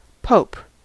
Ääntäminen
US : IPA : /poʊp/